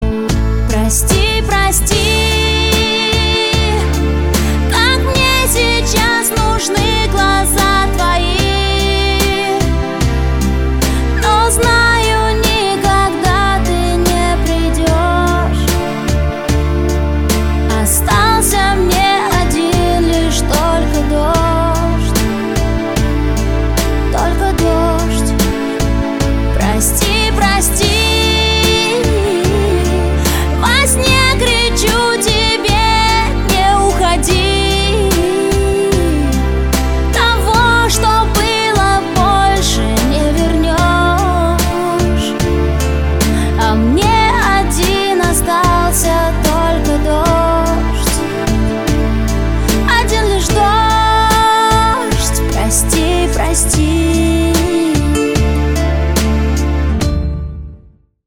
• Качество: 256, Stereo
гитара
женский вокал
грустные
романтичные
эстрадные